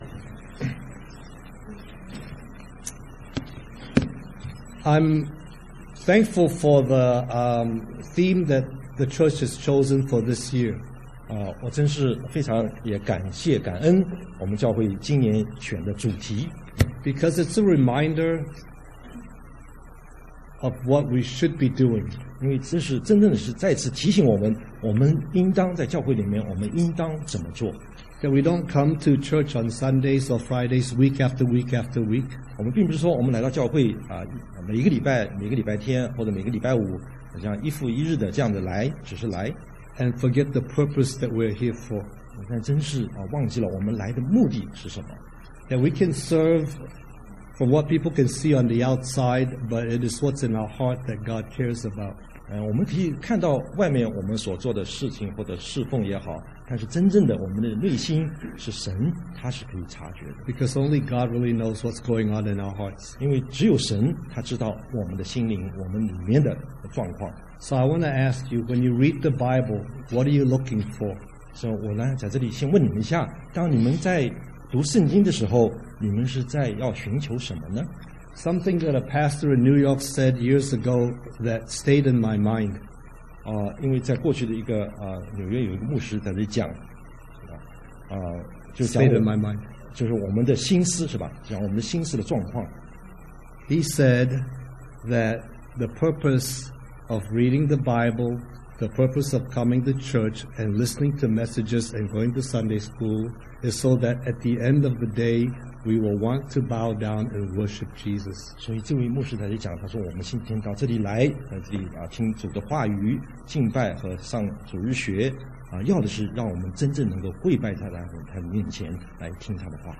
东区基督教会主日崇拜讲道信息